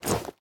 equip_gold4.ogg